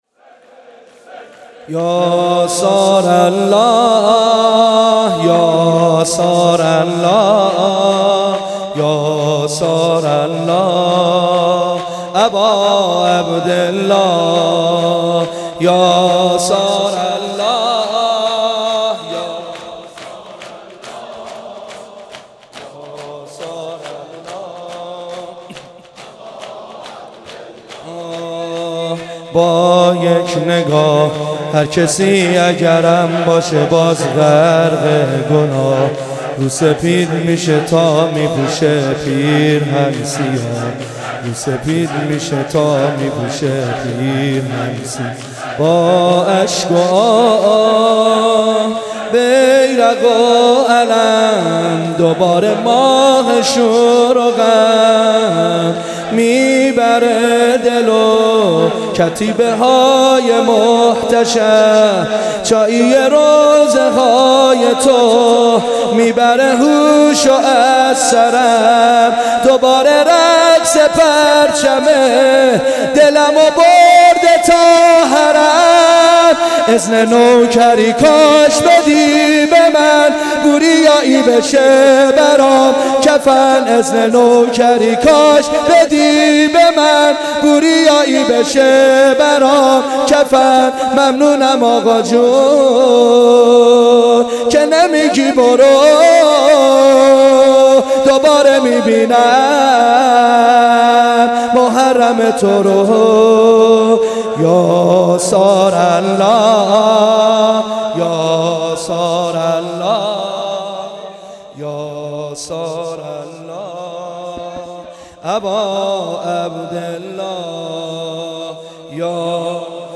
shor.mp3